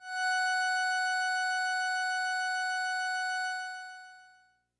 描述：通过Modular Sample从模拟合成器采样的单音。
Tag: F6 MIDI音符-90 罗兰木星-4 合成器 单注意 多重采样